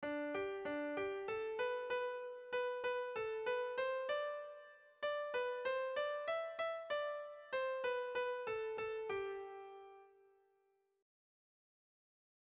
Erromantzea
Lauko txikia (hg) / Bi puntuko txikia (ip)
AB